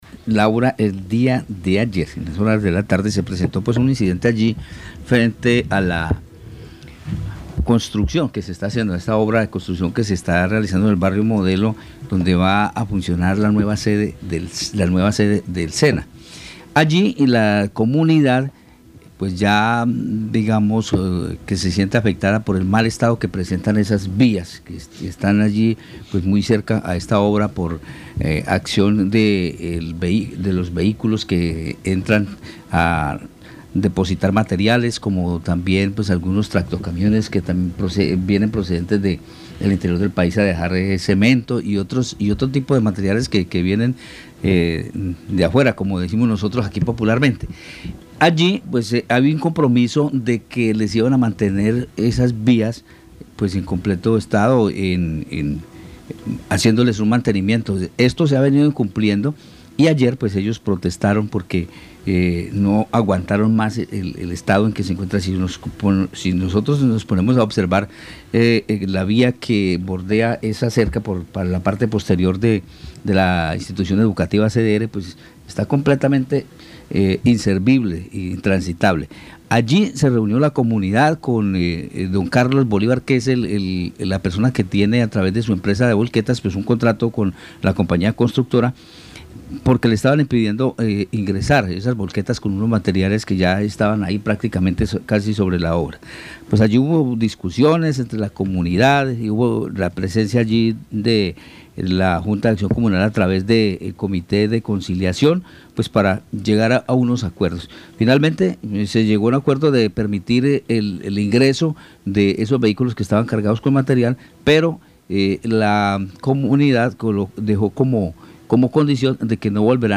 Momentos de la protesta en el ingreso a la obra de construcción de la nueva sede del SENA Guaviare